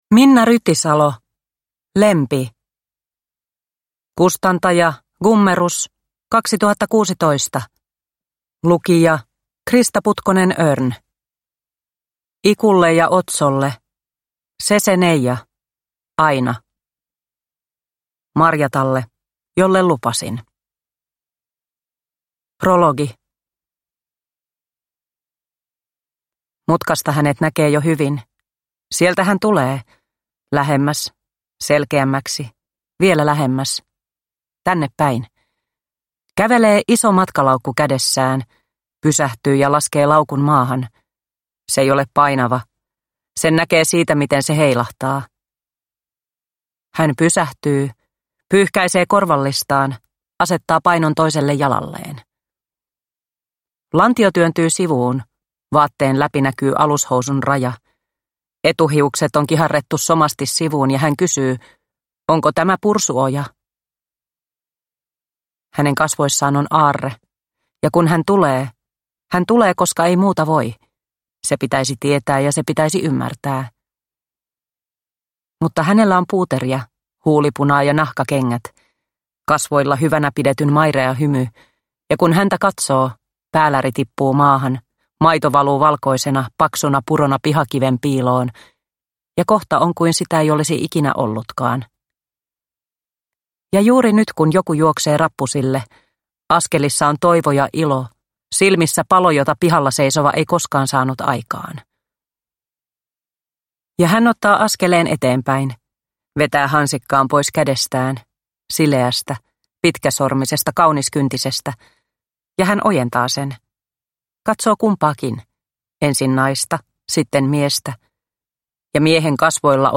Lempi – Ljudbok – Laddas ner